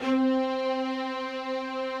strings_048.wav